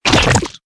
CHQ_FACT_paint_splash.ogg